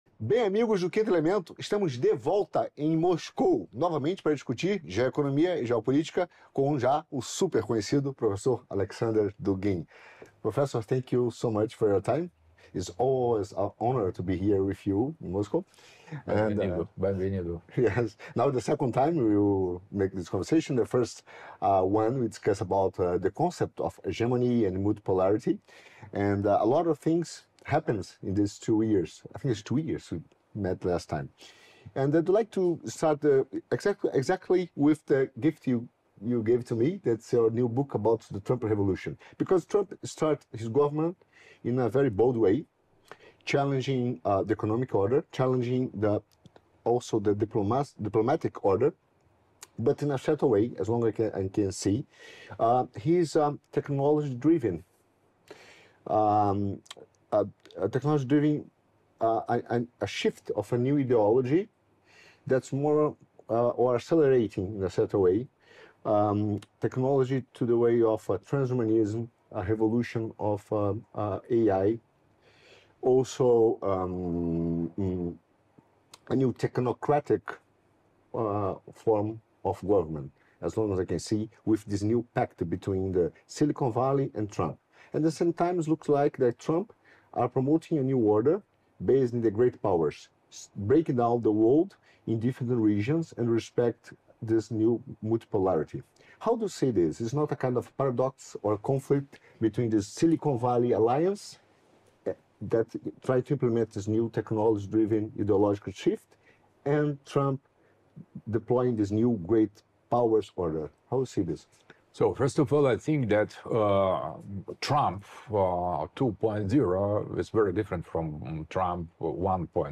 The True Face of the Trump Administration? | Interview with Alexander Dugin